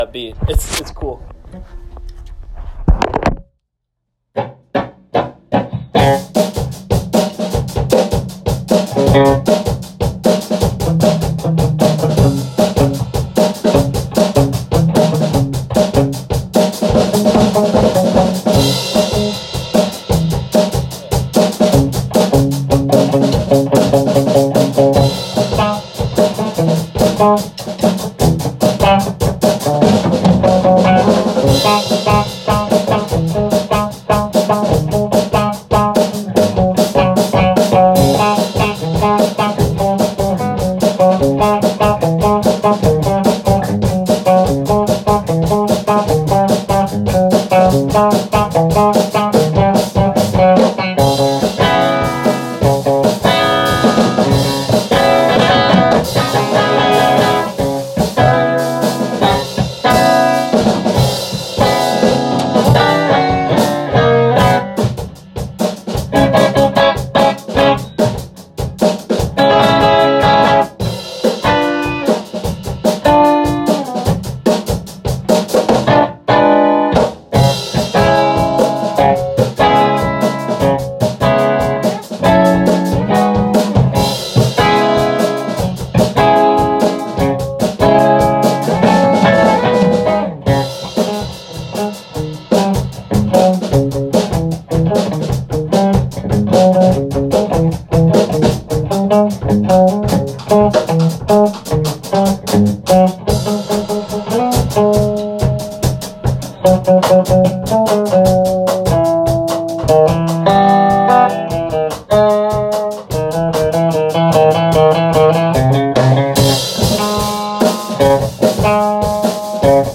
c#m jam fast funk drum.m4a